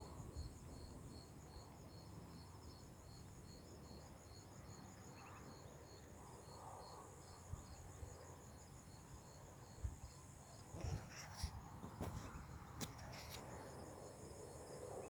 Batitú (Bartramia longicauda)
Nombre en inglés: Upland Sandpiper
Provincia / Departamento: Entre Ríos
Localización detallada: Villa Zorraquin
Condición: Silvestre
Certeza: Vocalización Grabada